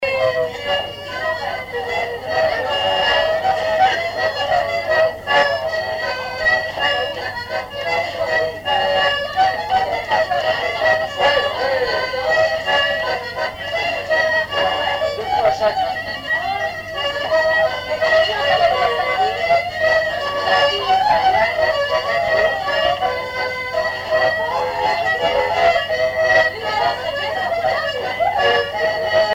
danse : scottich trois pas
Répertoire d'un bal folk par de jeunes musiciens locaux
Pièce musicale inédite